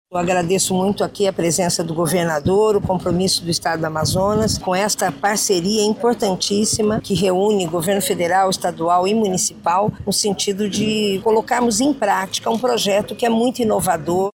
Durante a vistoria, a ministra das Mulheres, Márcia Lopes, explicou que o projeto vai dar resultados nas esferas municipal, estadual e federal.